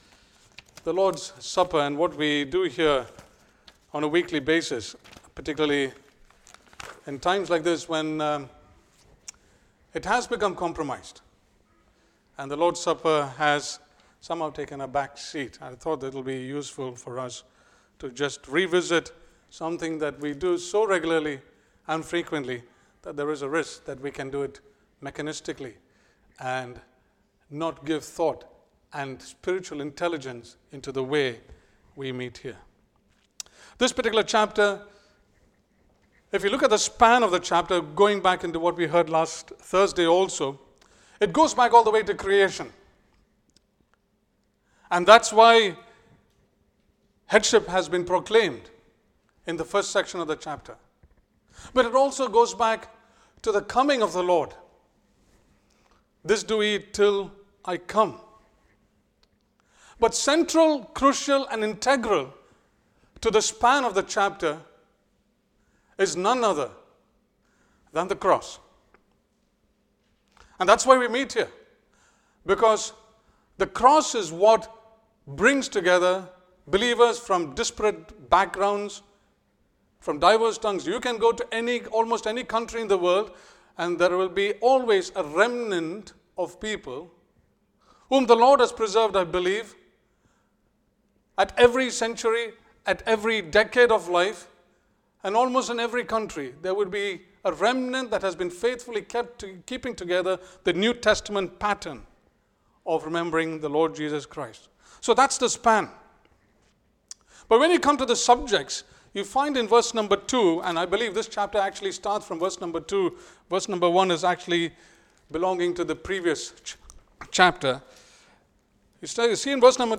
Assembly Teaching